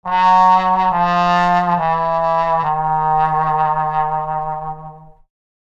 Download Free Cartoon Comedy Sound Effects | Gfx Sounds
Sad-trombone-wah-wah-wah-fail-comedy.mp3